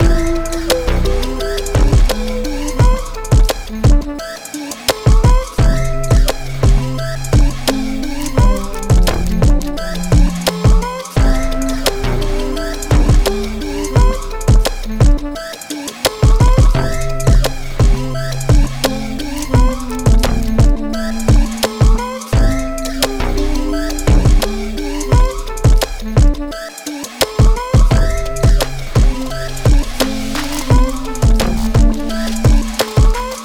A Minor